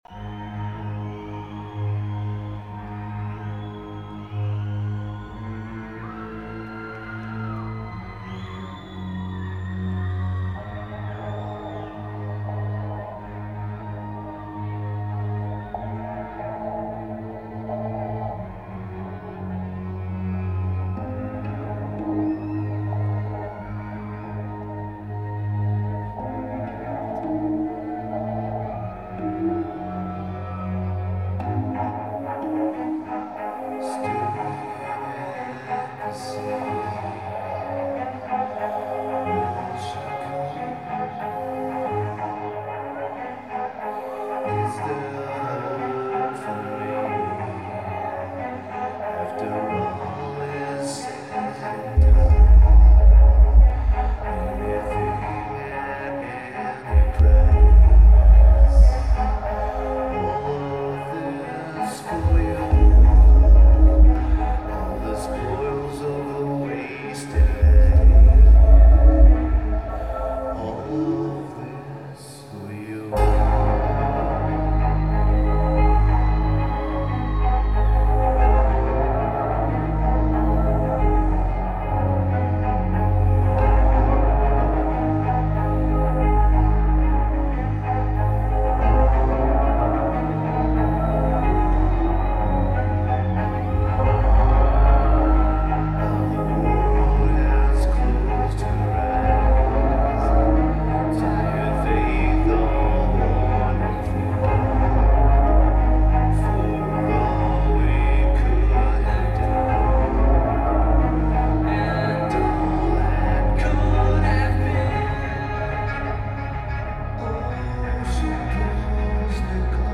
UIC Pavillion